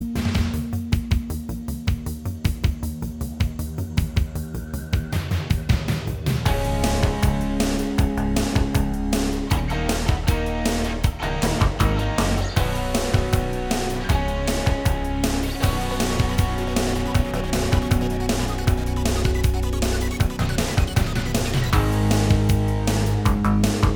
Minus All Guitars Soundtracks 3:30 Buy £1.50